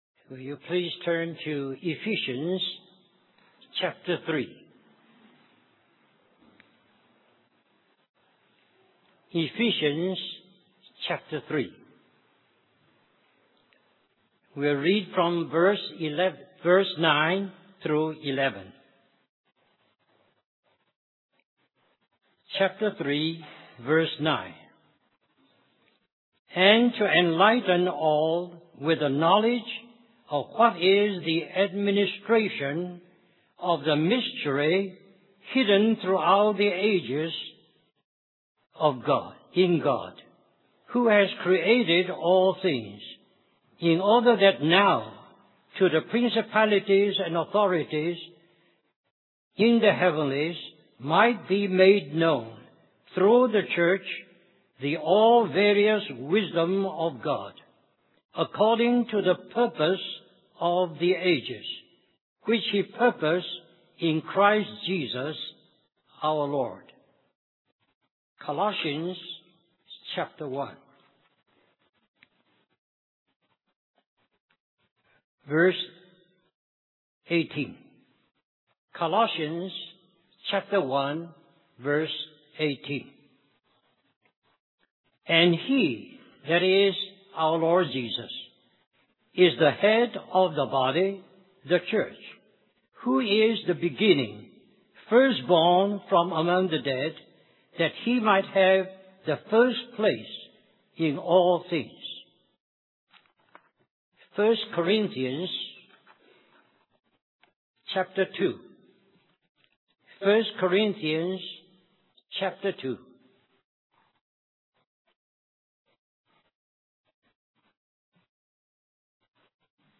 2004 Christian Family Conference